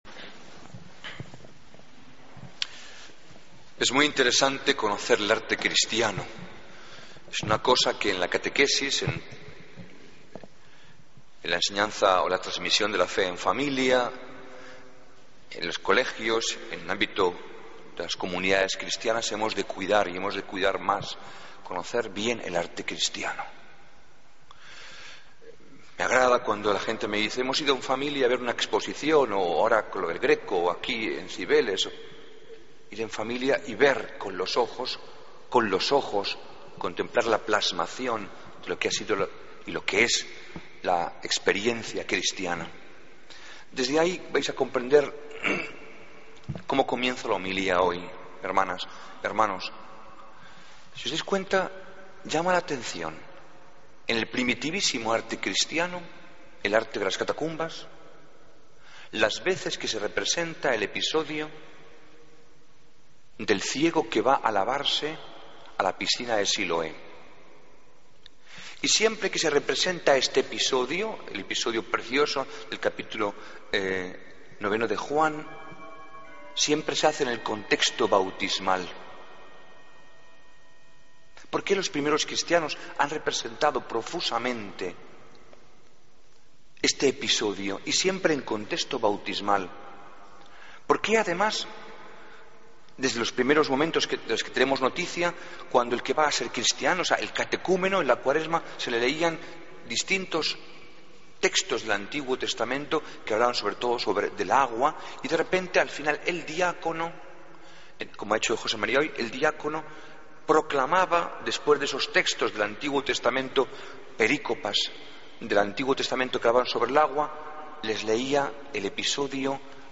Homilía del Domingo 30 de Marzo de 2014